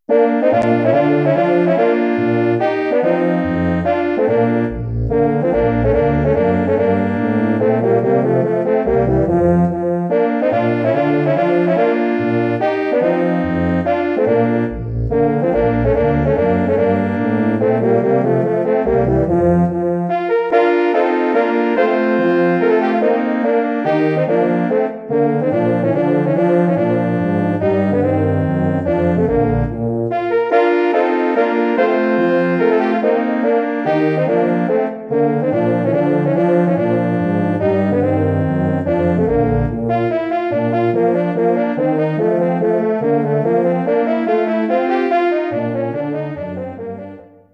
Arrangement / Hornquartett
Bearbeitung für Hornquartett
Besetzung: 4 Hörner
Arrangement for horn quartet
Instrumentation: 4 horns